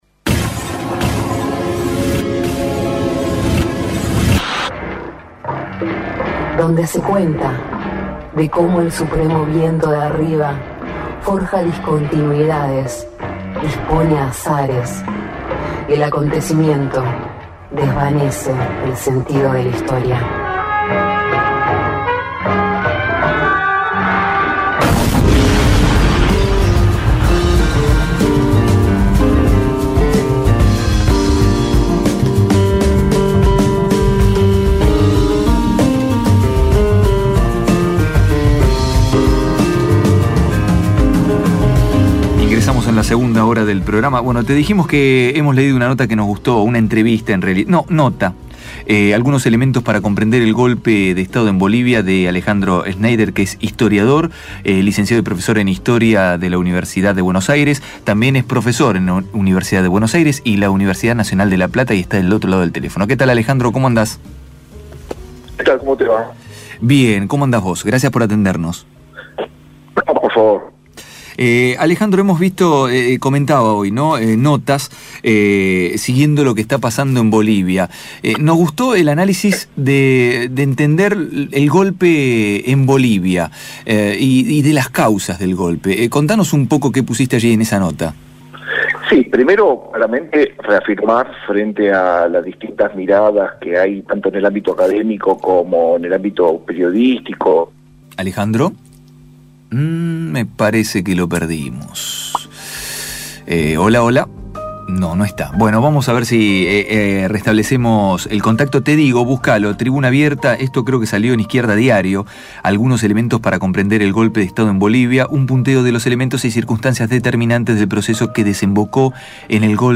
Entrevista AL DORSO